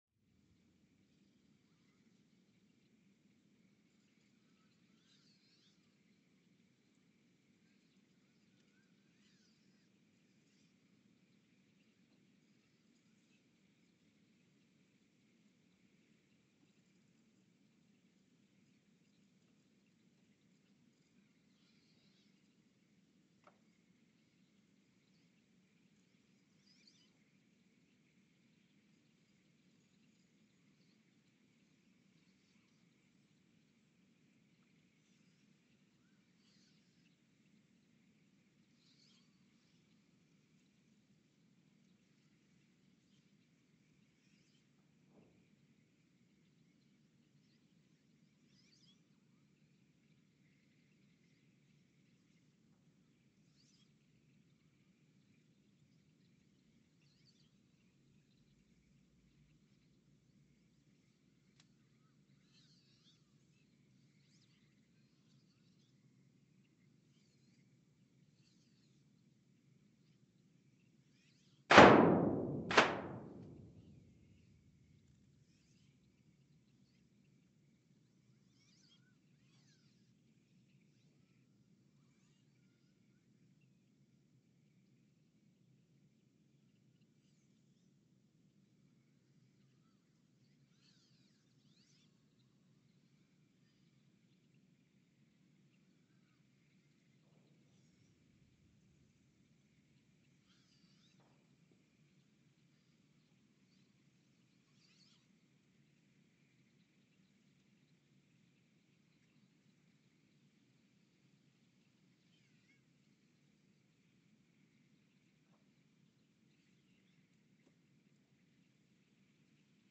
The Earthsound Project is an ongoing audio and conceptual experiment to bring the deep seismic and atmospheric sounds of the planet into conscious awareness.
Station : ULN (network: IRIS/USGS ) at Ulaanbaatar, Mongolia
Speedup : ×900 (transposed up about 10 octaves)
Loop duration (audio) : 11:12 (stereo)